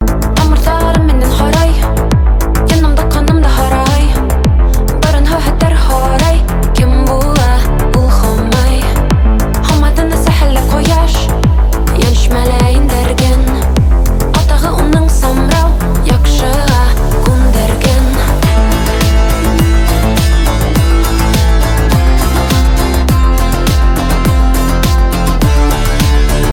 relax , мелодичные